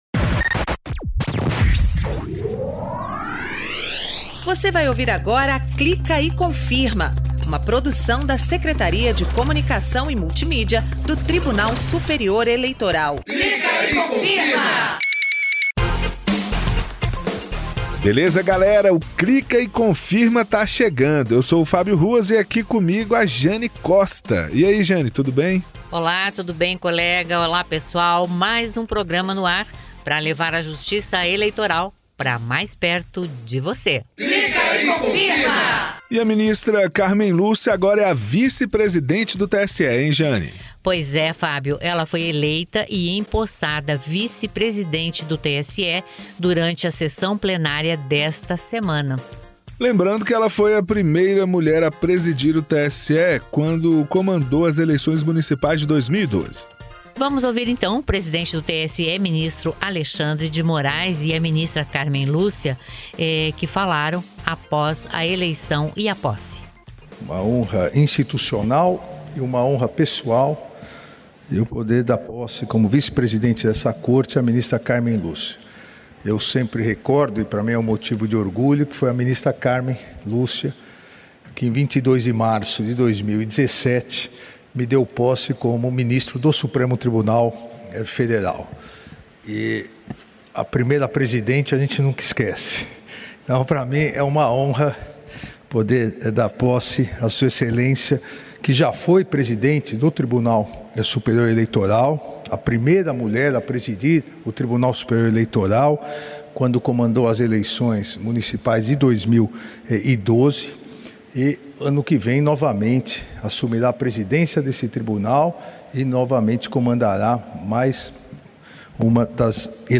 O Clica e Confirma desta semana traz um bate-papo com o juiz auxiliar da presidência do TSE, Paulo Bonini, que fala sobre a importância das missões de observação eleitoral para a democracia em todo o mundo. Ele representou o Brasil na Missão de Observação Eleitoral que esteve no Timor-Leste, de 17 a 24 de maio, para acompanhar as eleições legislativas do país asiático O programa também mostra como foram a eleição e a posse da ministra Cármen Lúcia no cargo de vice-presidente do TSE e também a posse do ministro Nunes Marques como membro efetivo da Corte Eleitoral.